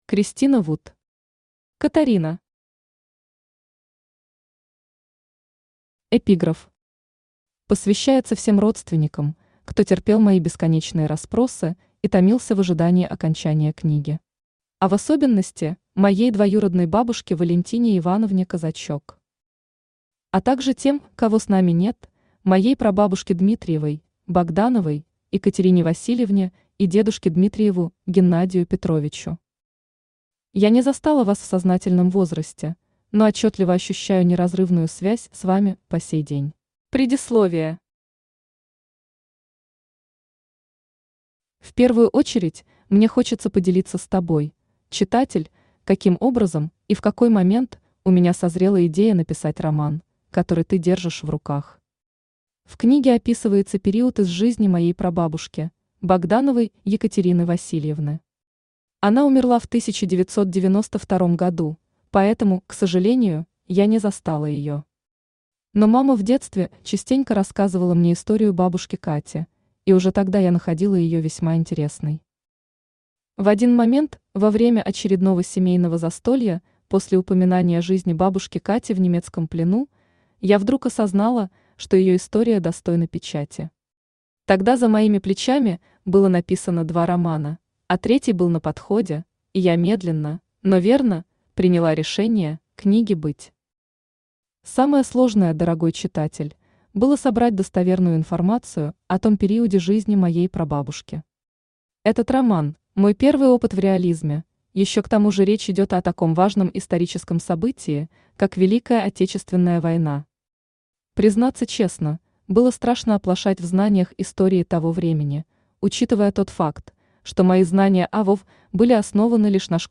Aудиокнига Катарина Автор Кристина Вуд Читает аудиокнигу Авточтец ЛитРес.